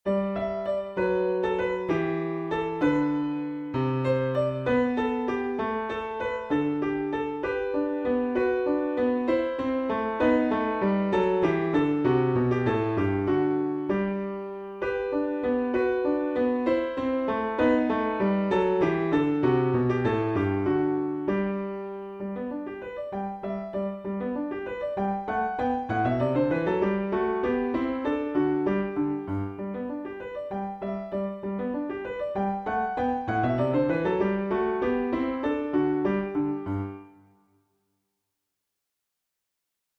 evoking the serene beauty of a starry night.